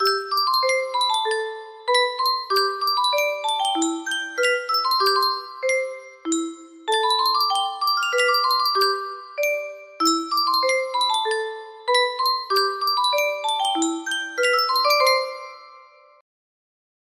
Yunsheng Music Box - Unknown Tune 1032 music box melody
Full range 60